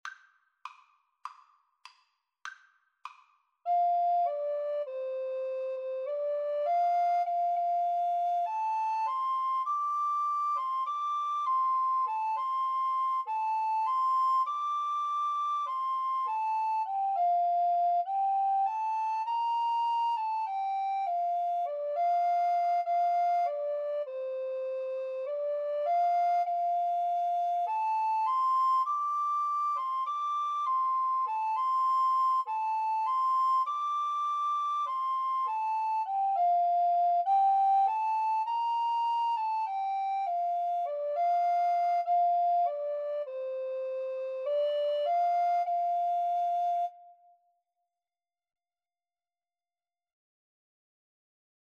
Play (or use space bar on your keyboard) Pause Music Playalong - Player 1 Accompaniment reset tempo print settings full screen
F major (Sounding Pitch) (View more F major Music for Alto Recorder Duet )
Moderato
Traditional (View more Traditional Alto Recorder Duet Music)